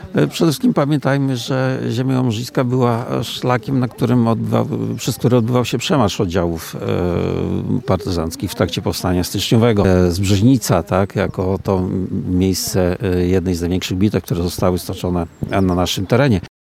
Głównym punktem uroczystości było podniesienie i poświęcenie krzyża na „Mokrej Łączce” – miejscu kaźni bohaterów Powstania.
Profesor Krzysztof Sychowicz, dyrektor białostockiego oddziału IPN, mówił o historycznej roli Ziemi Łomżyńskiej podczas Powstania.